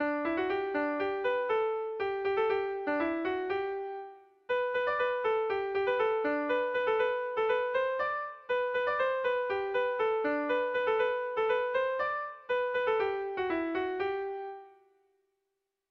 Kontakizunezkoa
ABD1D2